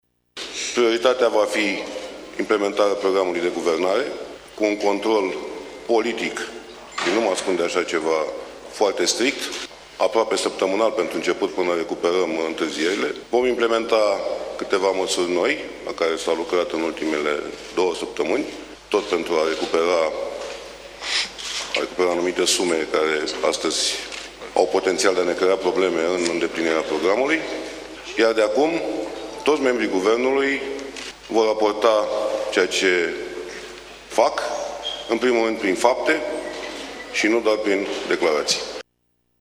Premierul desemnat de PSD, Mihai Tudose, a declarat că nu consideră că a câștigat vreun premiu și că de acum toți membrii guvernului vor lucra pentru implementarea programului de guvernare: